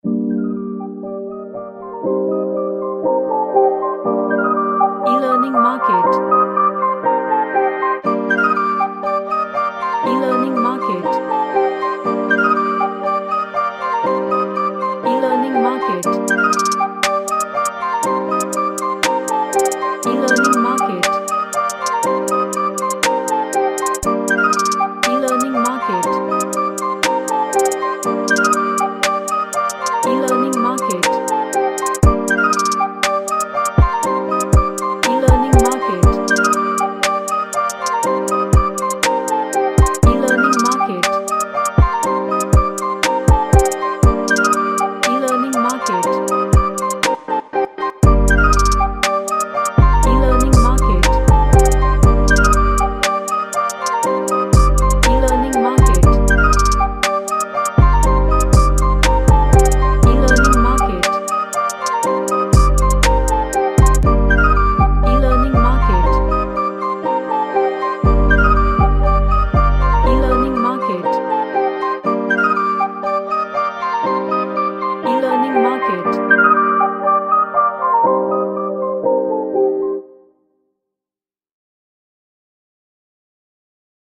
A hard hitting hip hop track
Chill Out